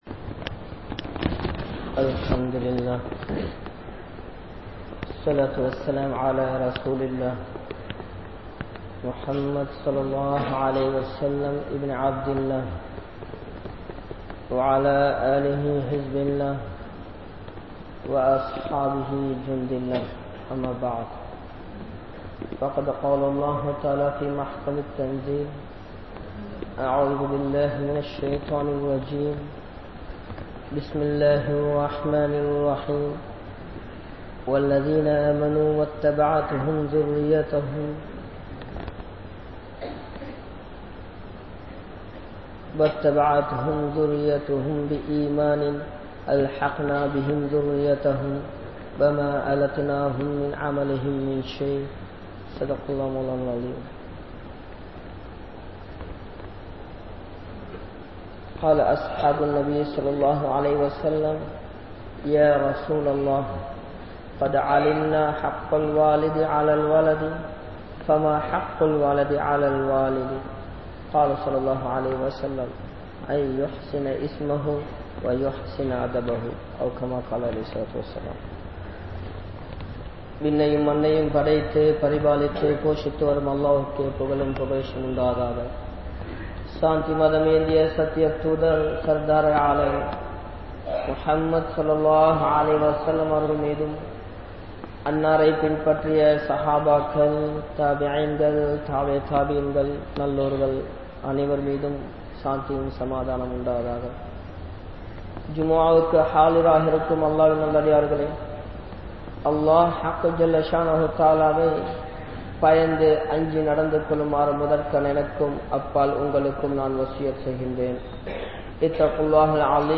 Indraya Nursery in Avala Nilai (இன்றைய பாலர்பாடசாலையின் அவலநிலை) | Audio Bayans | All Ceylon Muslim Youth Community | Addalaichenai